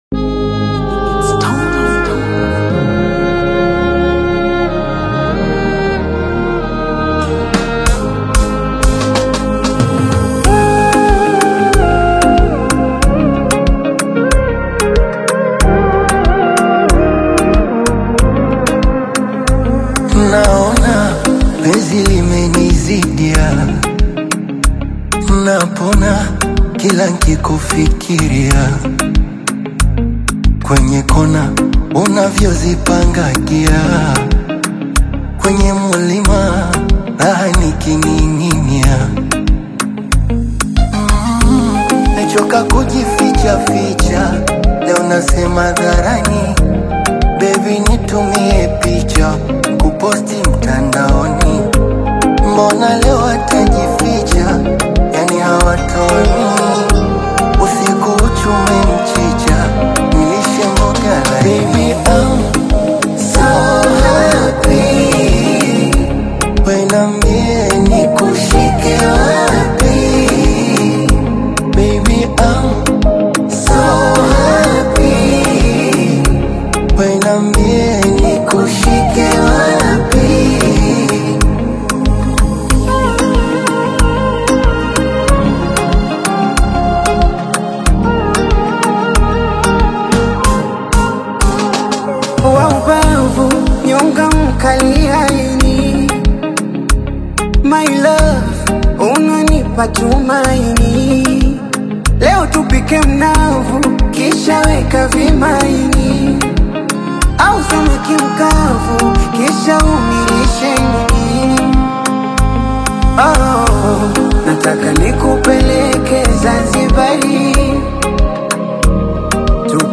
delivers his verses with a smooth, soulful tone